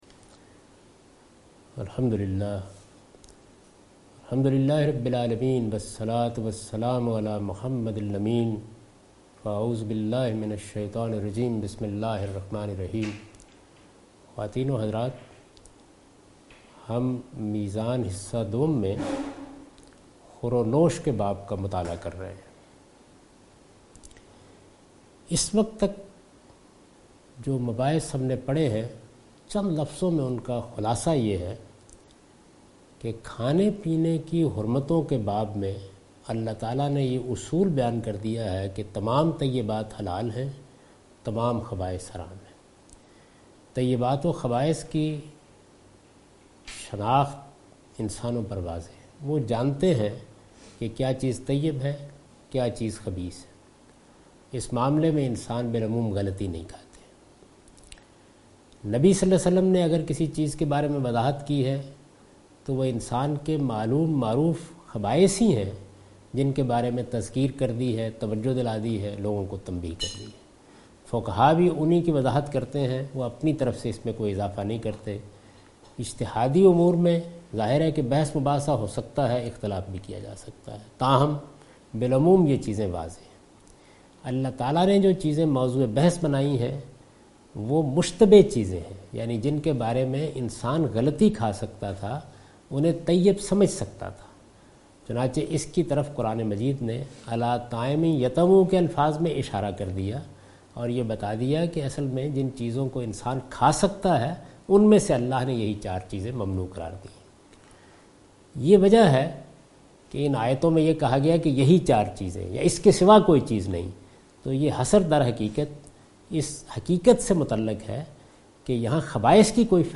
A comprehensive course taught by Javed Ahmed Ghamidi on his book Meezan. In this lecture he will discuss the dietary shari'ah. He explains Islamic law regarding edibles.